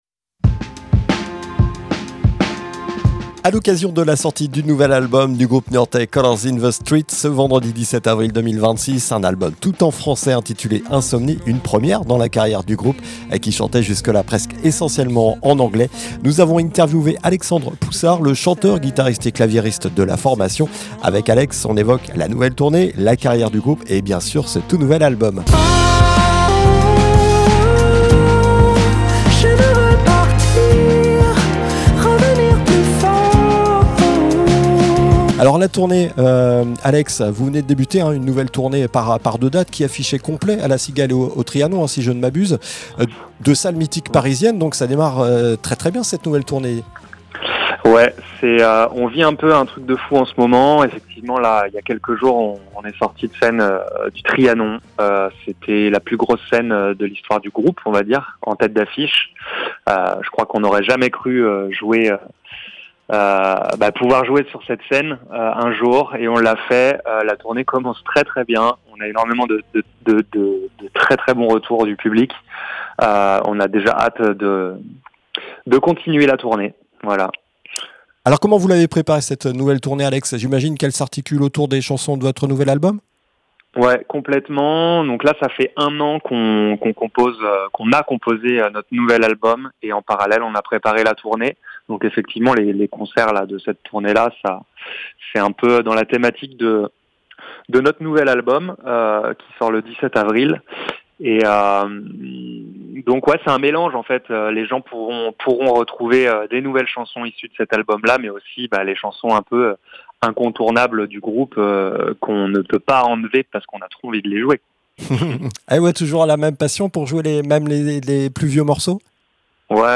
nous avons interviewé